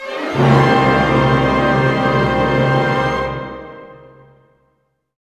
Index of /90_sSampleCDs/Roland - String Master Series/ORC_Orch Gliss/ORC_Minor Gliss